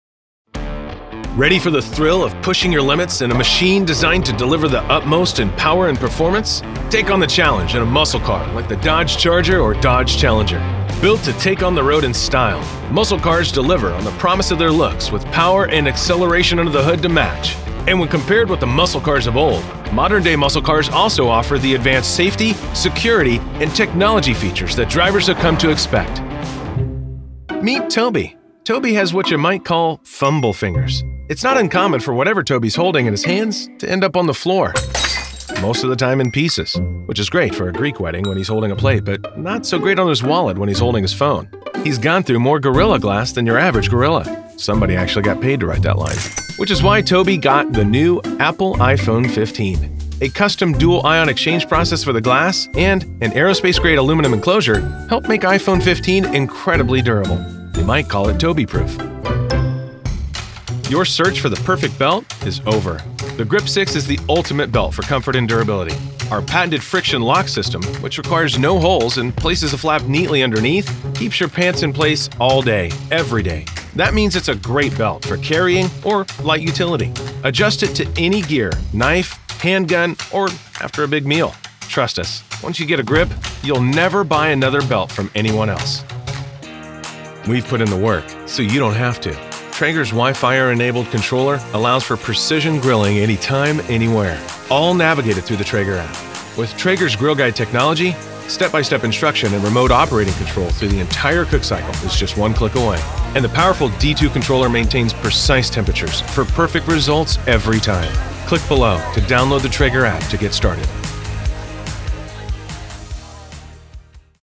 Male
English (North American)
Yng Adult (18-29), Adult (30-50)
My voice has been described as warm, genuine, authentic, trustworthy, authoritative, knowledgeable, inviting, engaging, encouraging, high-energy, believable, down-to-earth, informative, sincere, big, booming, and relatable.
Explainer Demo
Words that describe my voice are warm, genuine, trustworthy.